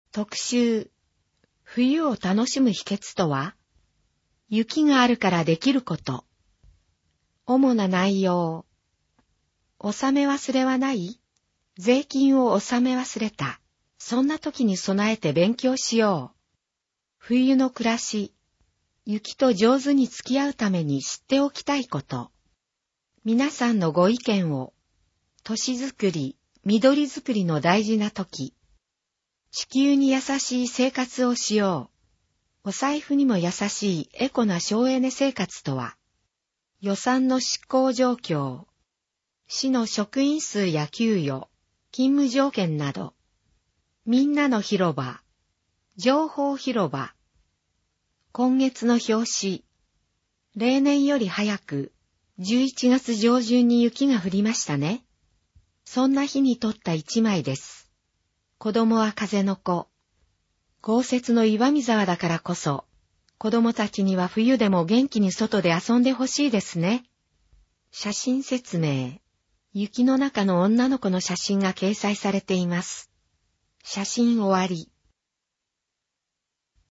声の広報（MP3）